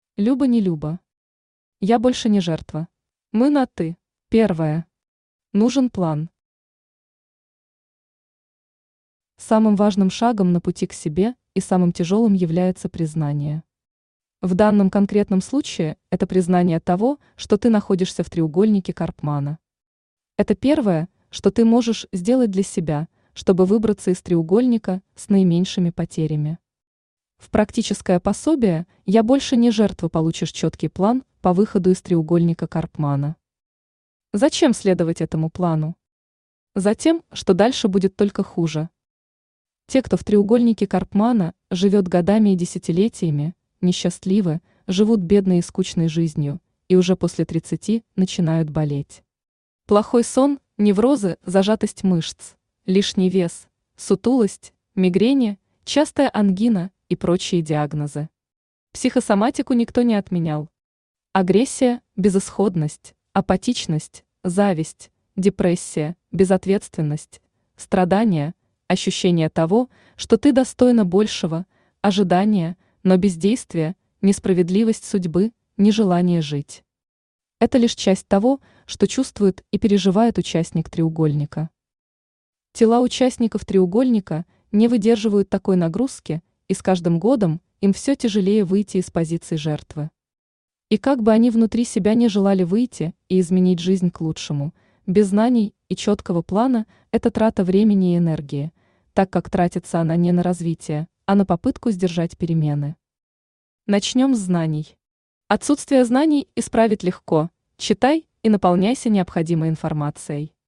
Aудиокнига Я больше не жертва Автор Люба Нелюба Читает аудиокнигу Авточтец ЛитРес.